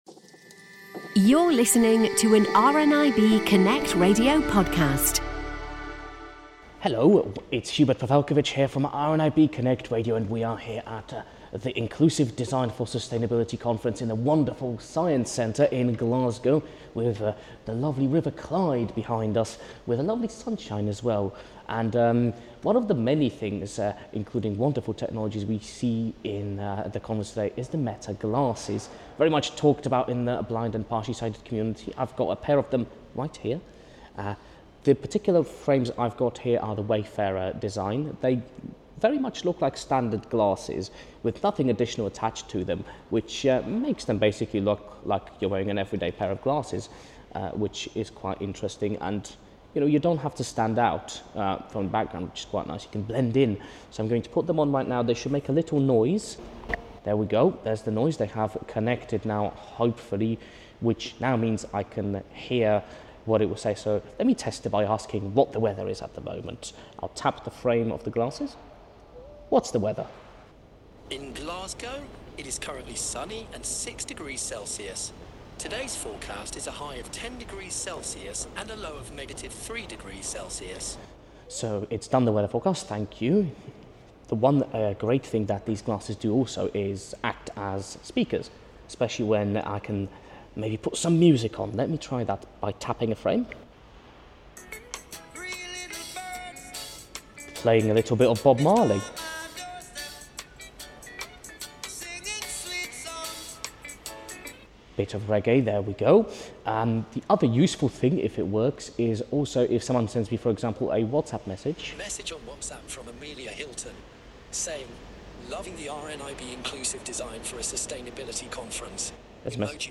A particular piece of technology has been getting a fair bit of buzz recently due to some nifty accessibility features. With a little demo at the big tech conference in Glasgow